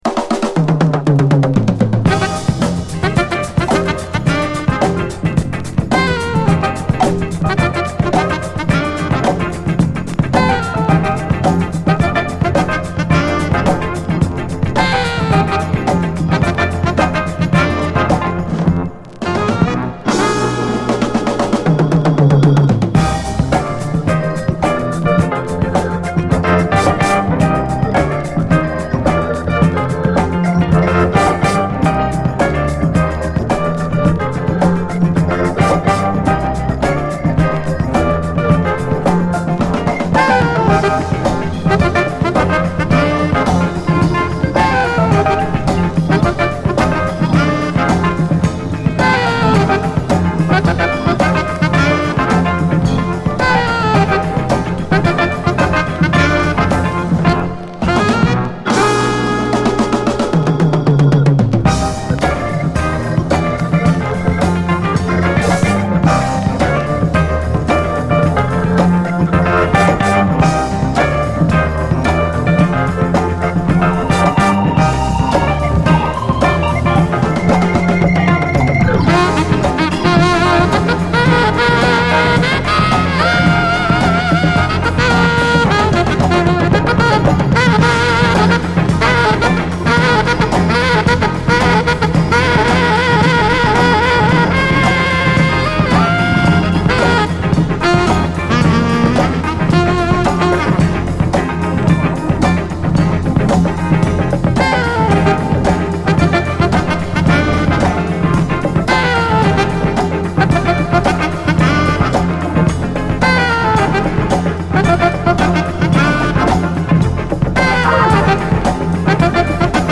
SOUL, JAZZ FUNK / SOUL JAZZ, 70's～ SOUL, JAZZ, 7INCH
猛然とスリリングに疾走するキラー・ファンク
サックス＆オルガンが熱く唸りをあげる！フルートが舞うメロウ・ジャズ・ファンク